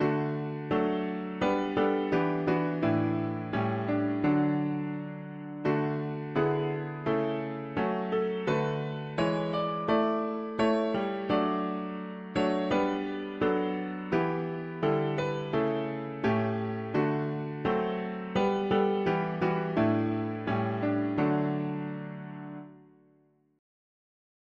Key: D major
Tags english theist 4part